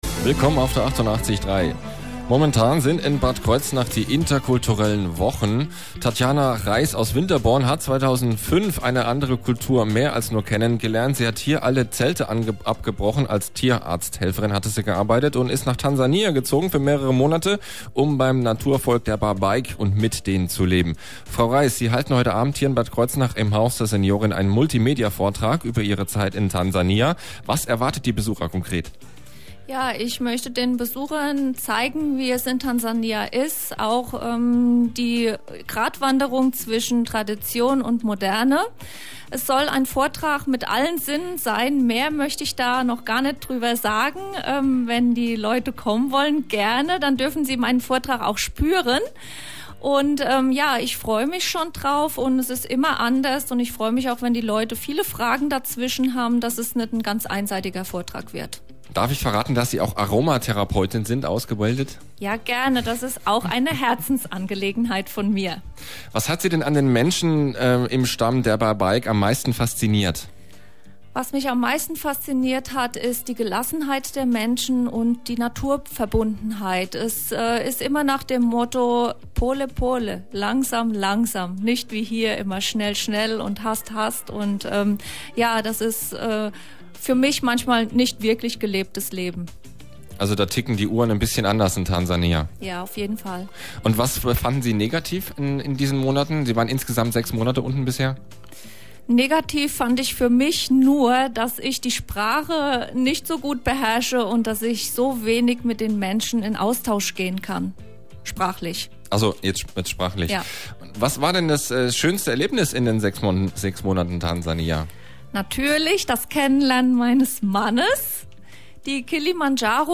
Radiointerview bei Antenne Kaiserslautern und Antenne Bad Kreuznach
In so einem Studio ist es echt spannend und Hut ab vor den Moderatoren, es ist ein wirklich anstrengender Job!